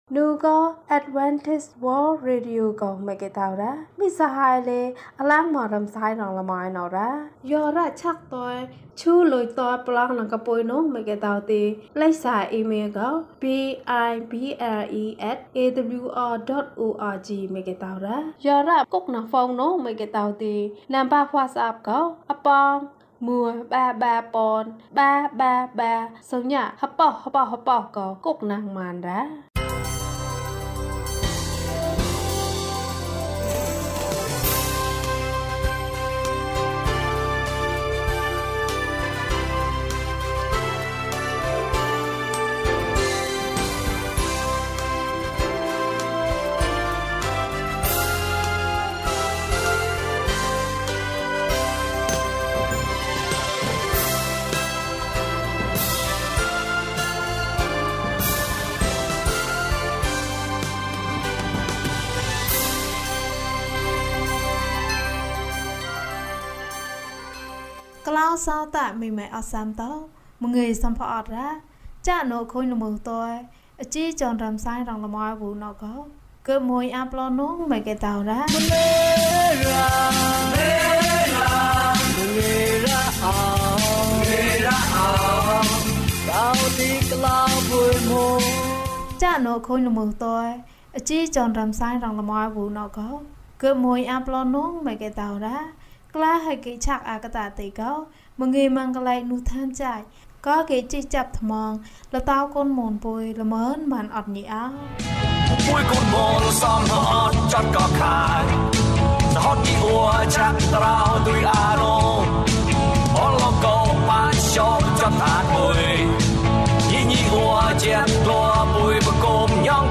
ရှင်ဘုရင်။ ကျန်းမာခြင်းအကြောင်းအရာ။ ဓမ္မသီချင်း။ တရားဒေသနာ။